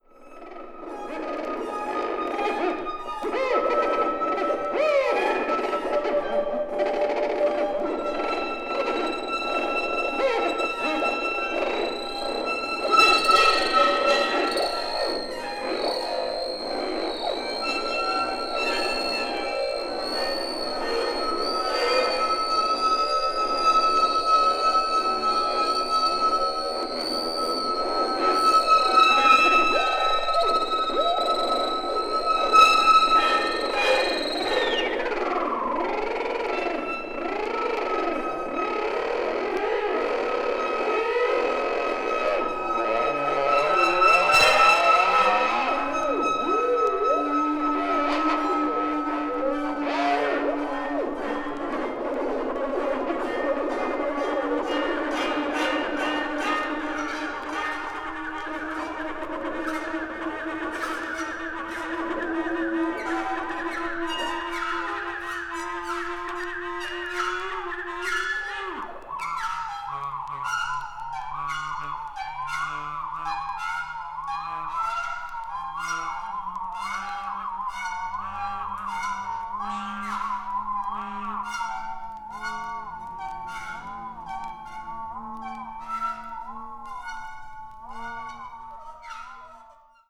avant-garde   experimental   free improvisation   sound art